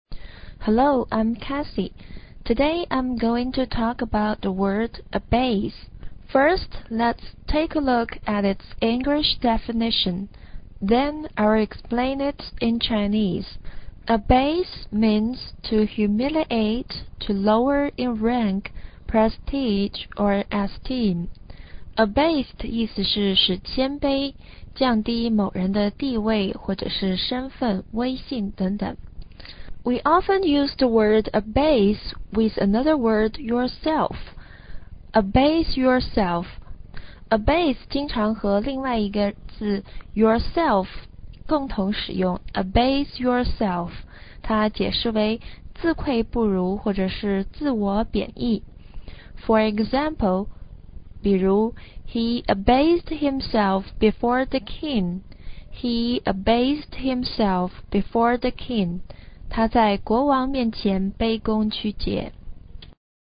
另外大家注意一下，abase在发音的时候，重音是在后面的base上的。